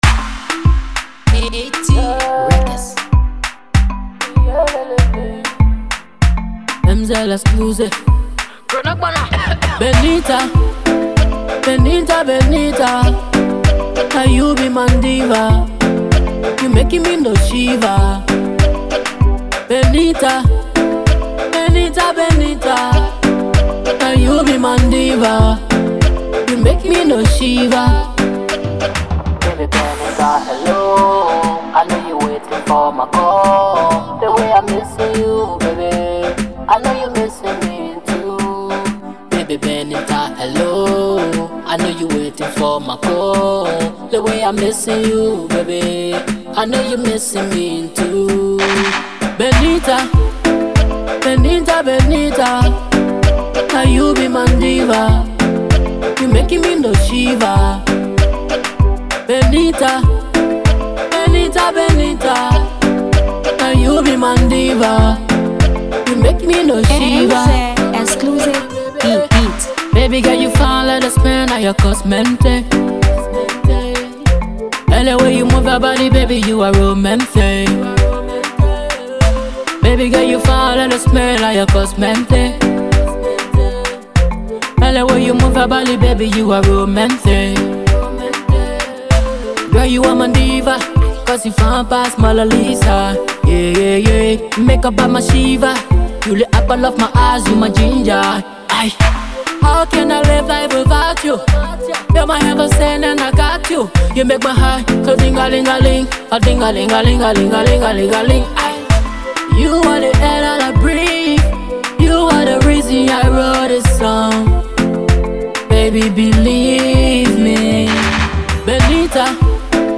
/ Afro-Pop / By